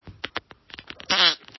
Ugly Fart Efeito Sonoro: Soundboard Botão
Ugly Fart Botão de Som